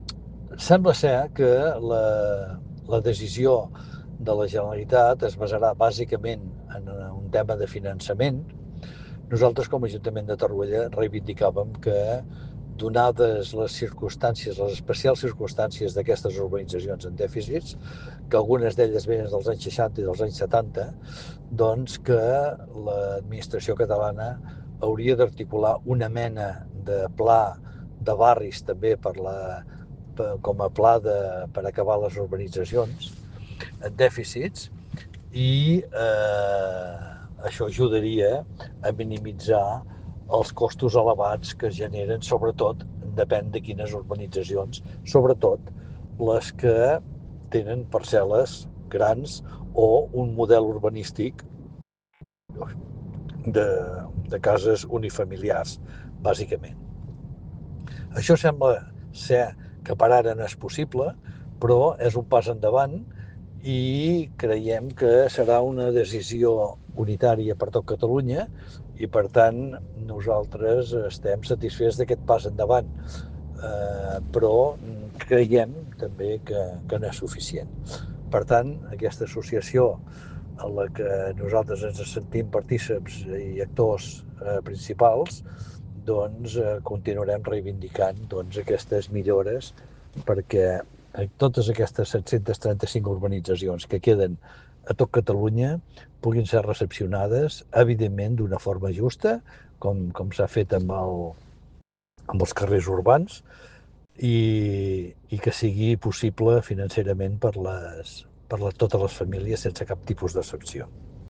Per això, i tal com explica l’alcalde del municipi, Jordi Colomí, s’està reivindicant un canvi en la legislació catalana. A més, parla del cas concret de Torroella.